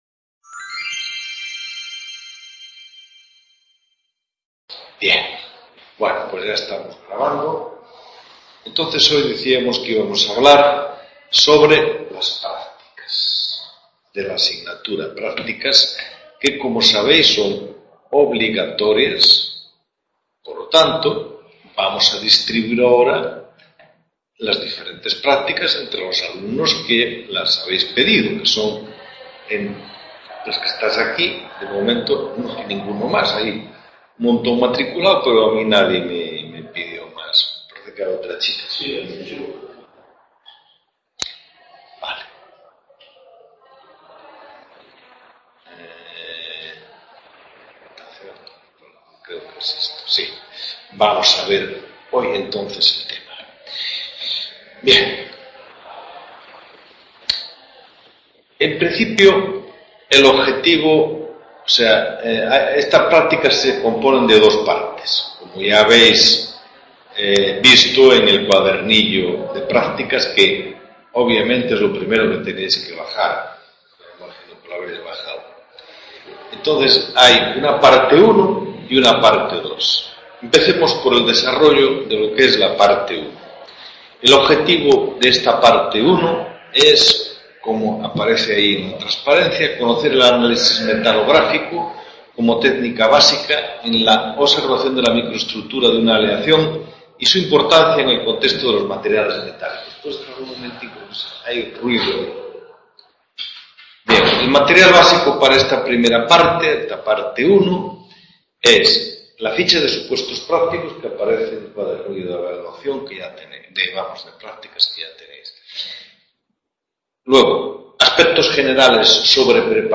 TUTORIA